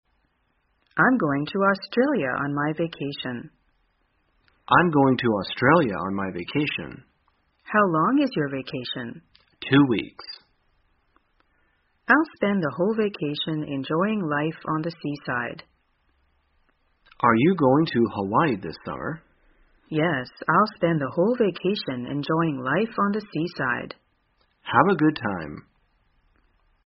在线英语听力室生活口语天天说 第219期:怎样谈论假期安排的听力文件下载,《生活口语天天说》栏目将日常生活中最常用到的口语句型进行收集和重点讲解。真人发音配字幕帮助英语爱好者们练习听力并进行口语跟读。